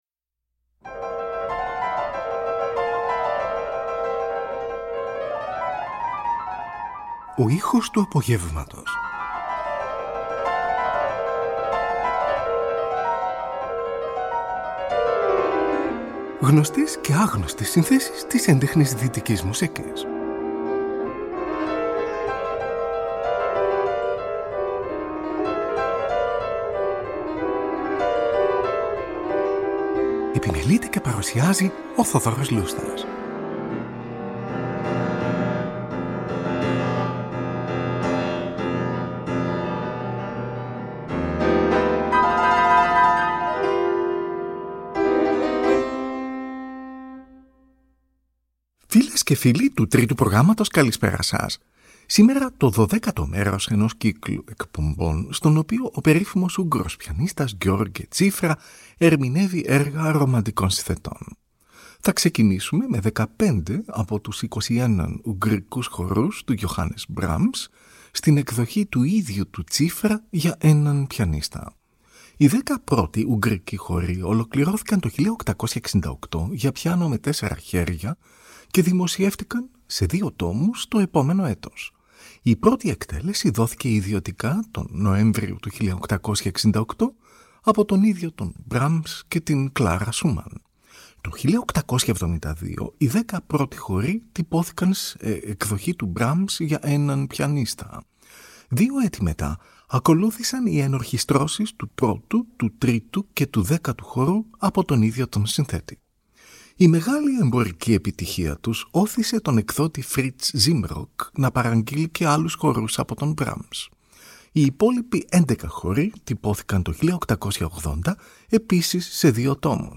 για έναν πιανίστα